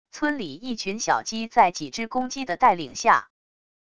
村里一群小鸡在几只公鸡的带领下wav音频